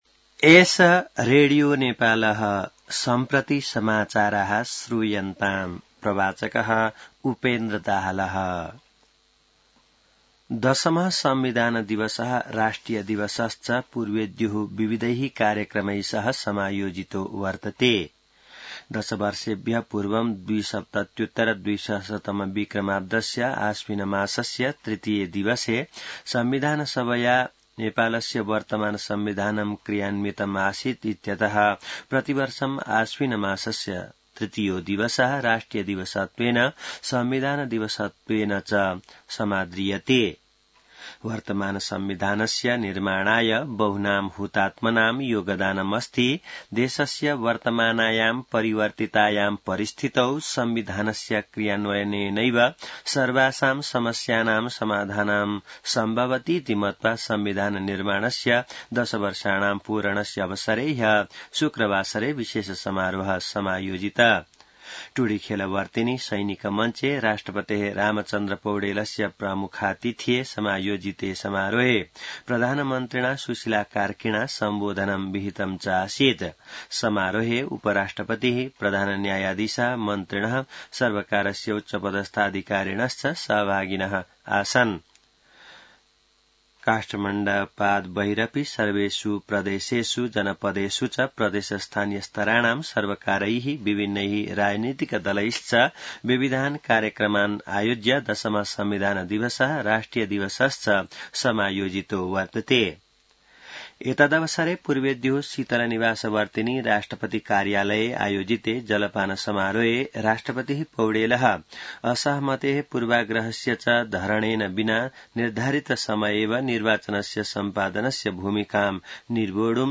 An online outlet of Nepal's national radio broadcaster
संस्कृत समाचार : ४ असोज , २०८२